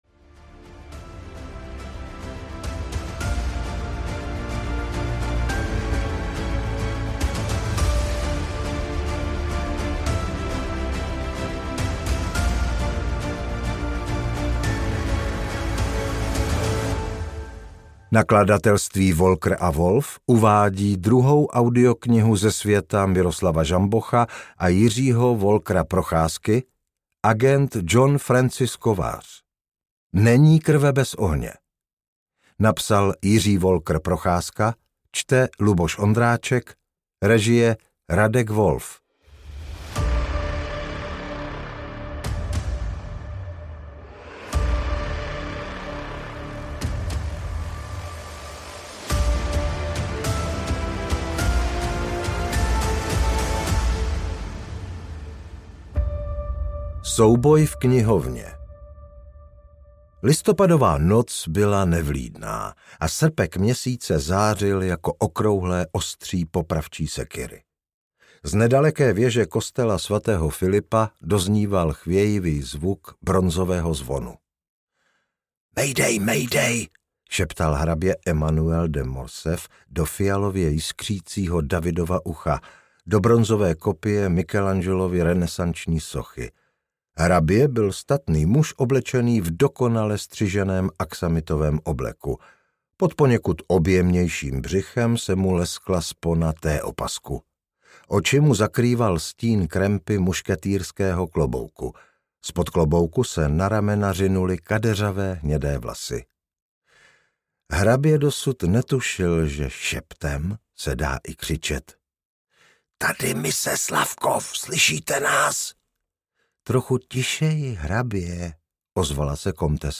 Není krve bez ohně audiokniha
Ukázka z knihy